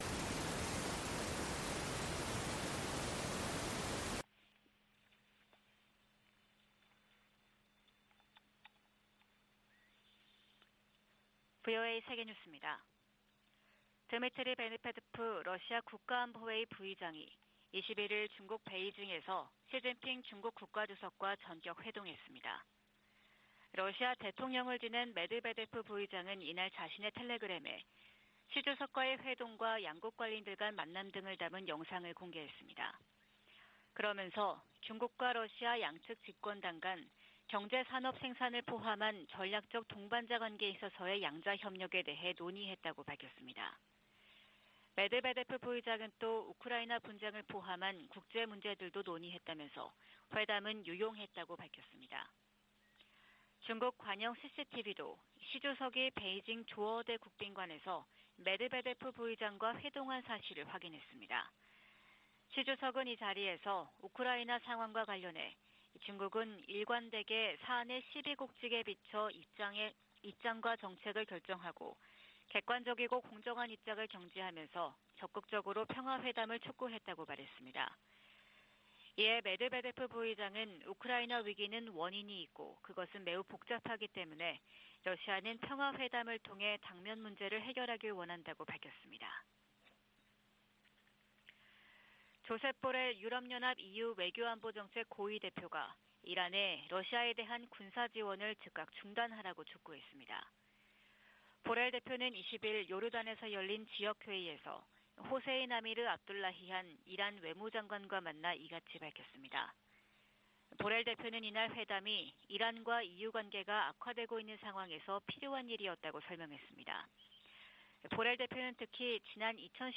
VOA 한국어 '출발 뉴스 쇼', 2022년 12월 22일 방송입니다. 미 국무부는 북한의 7차 핵실험이 정치적 결단만 남았다며, 이를 강행시 추가 조치를 취하겠다고 밝혔습니다. 미국 국방부가 북한의 도발 억제를 위해 역내 동맹들과 긴밀하게 협력할 것이라고 거듭 밝혔습니다.